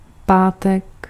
Ääntäminen
Synonyymit dredi Ääntäminen France (Ouest): IPA: [vɑ̃.dʁə.ˈdi] France: IPA: [vɑ̃.dʁə.ˈdi] Haettu sana löytyi näillä lähdekielillä: ranska Käännös Ääninäyte Substantiivit 1. pátek {m} Suku: m .